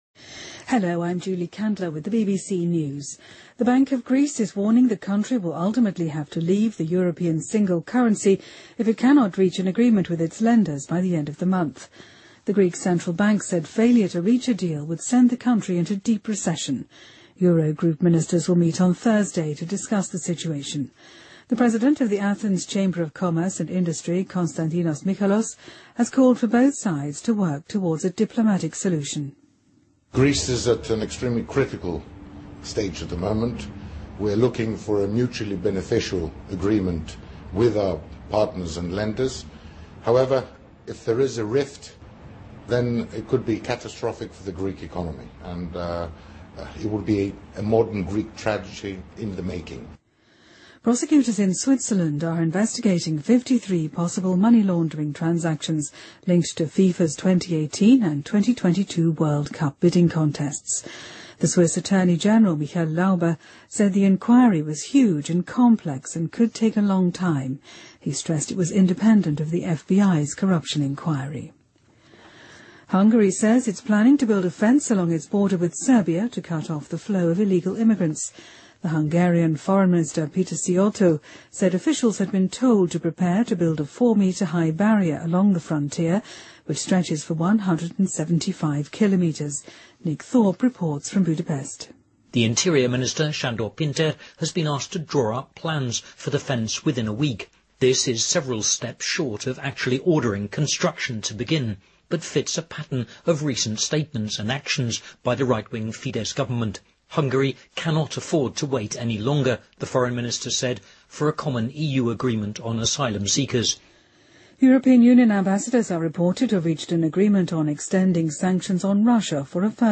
BBC news,欧盟延长对俄罗斯经济制裁